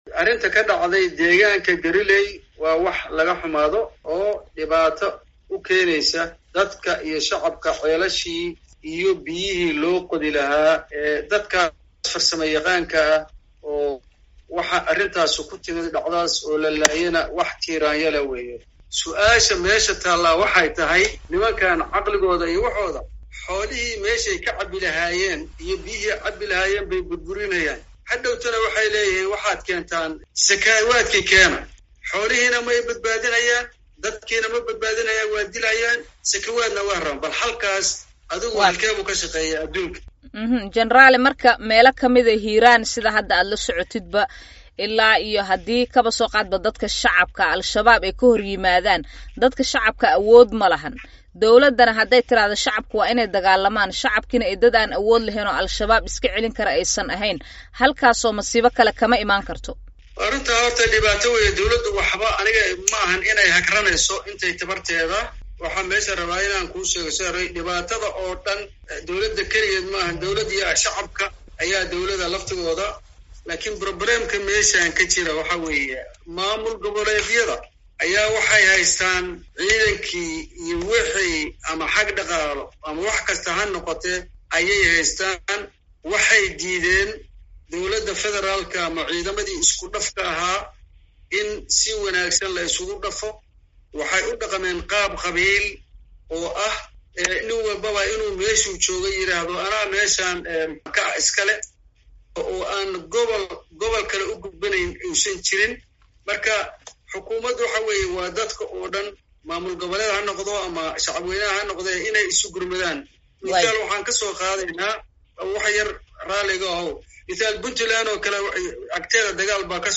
Wareysiga General Diini.mp3